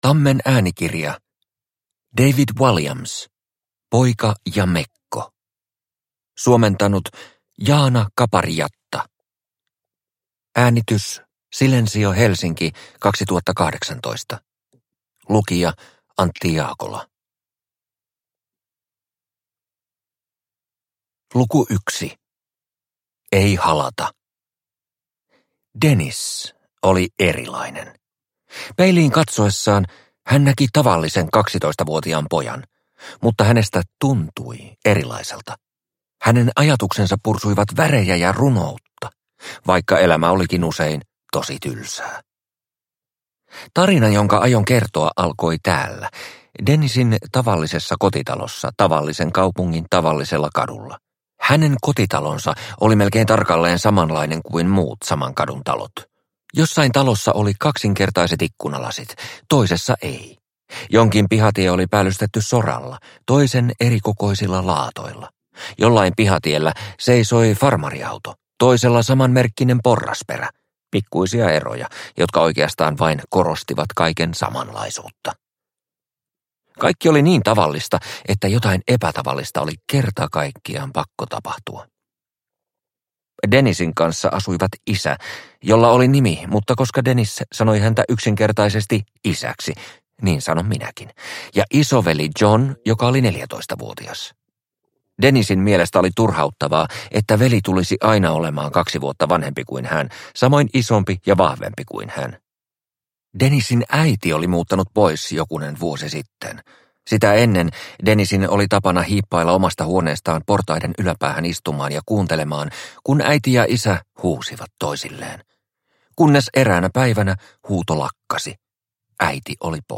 Poika ja mekko – Ljudbok